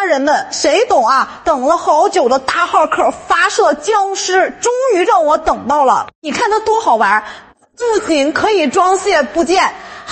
充满活力的玩具评测AI配音
开箱旁白
充满活力